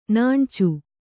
♪ nāṇcu